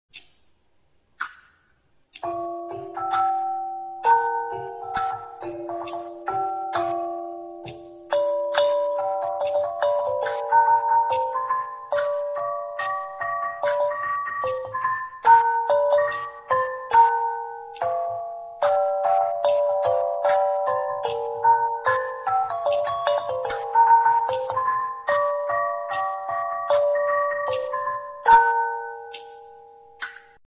Khawng Wong Yai
The “circle of gongs” is a further development of the single gong.